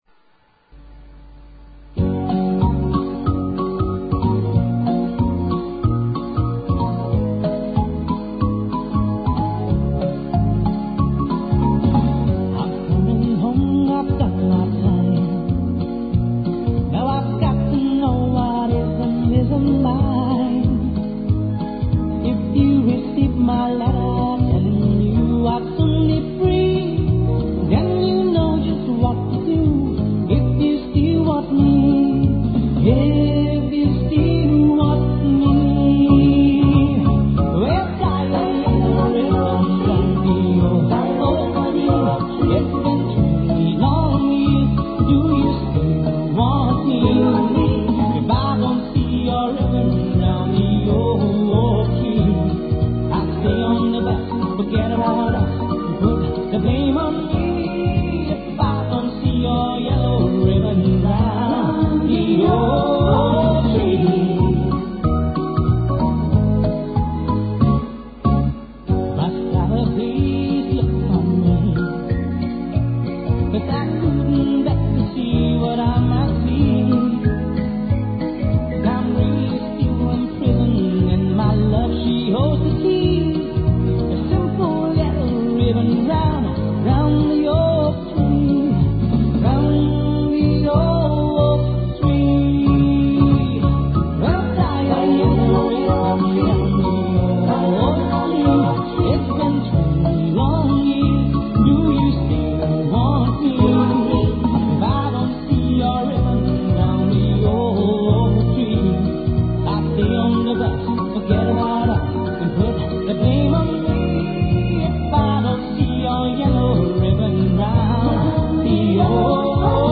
很欢快的曲子啊。